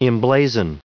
1285_emblazon.ogg